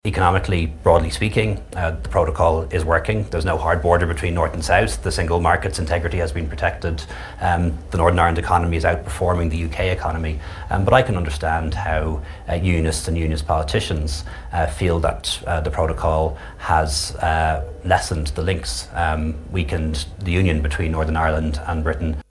Speaking to the BBC, the Taoiseach said he understands Unionist concerns about the protocol: